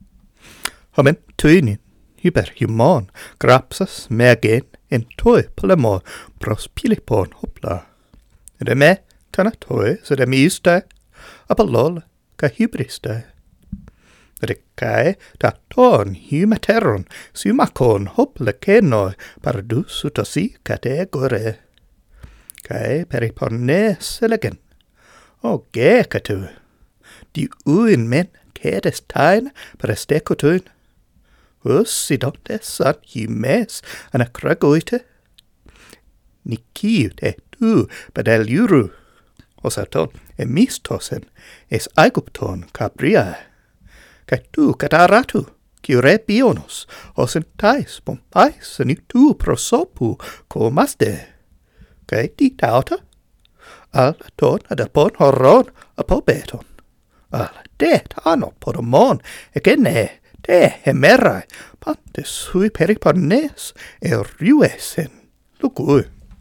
Yeah, the higher pitch is unnatural.